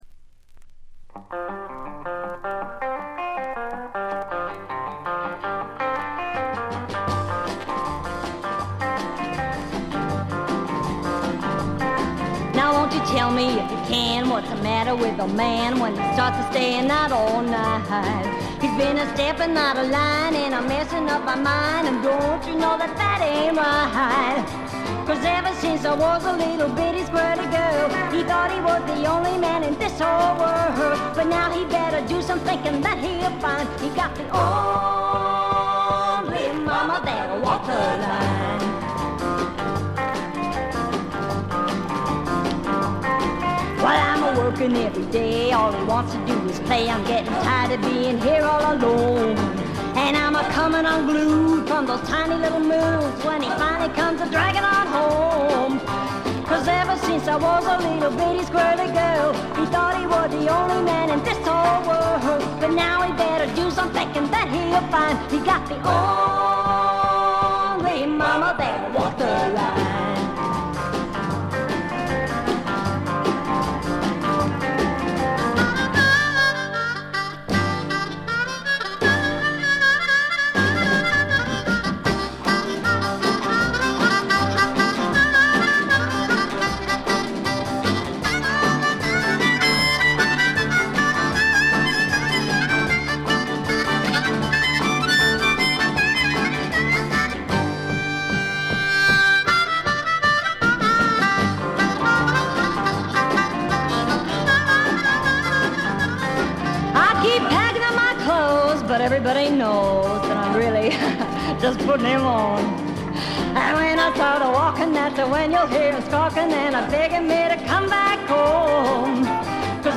軽微なバックグラウンドノイズ。散発的なプツ音が2-3回程度。
試聴曲は現品からの取り込み音源です。